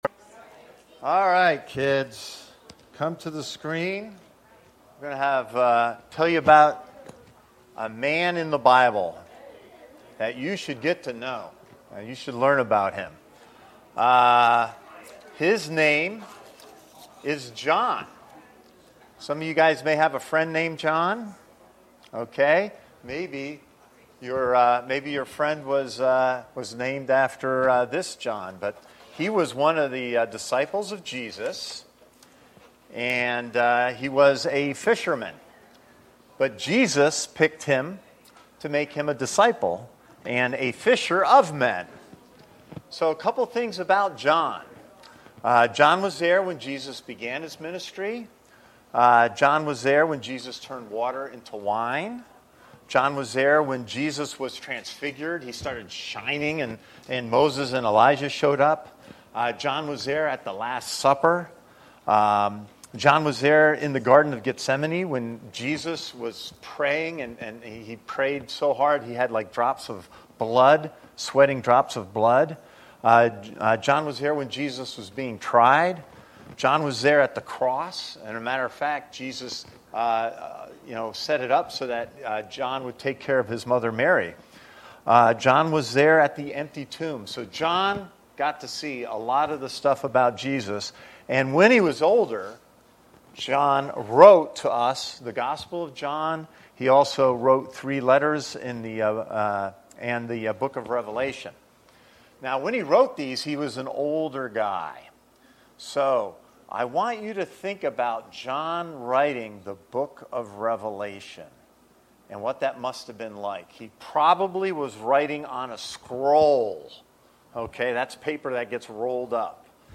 This is the main Sunday Service for Christ Connection Church